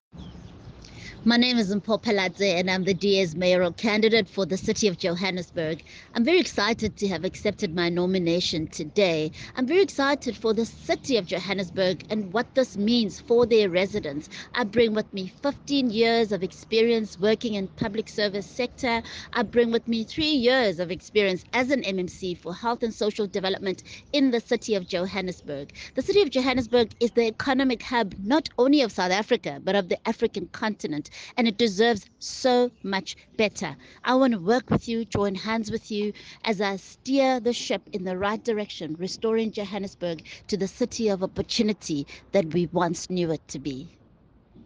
Issued by Mpho Phalatse – DA City of Johannesburg Mayoral Candidate
soundbite by Mpho Phalatse.